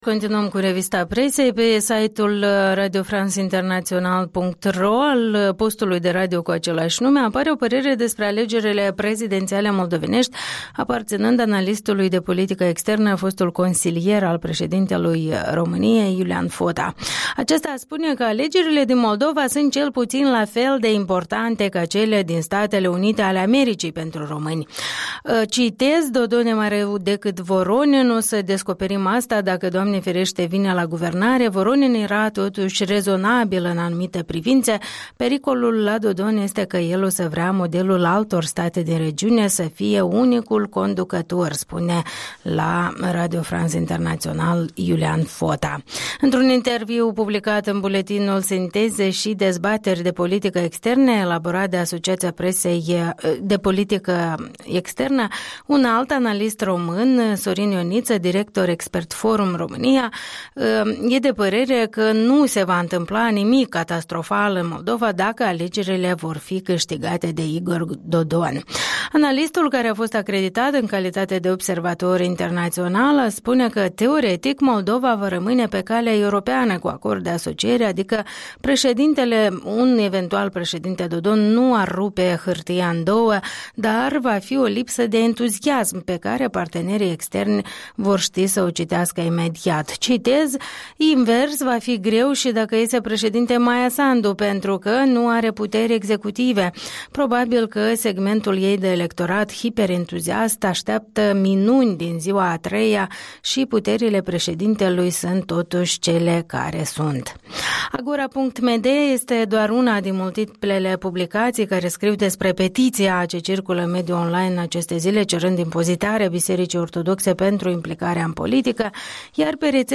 Revista presei matinale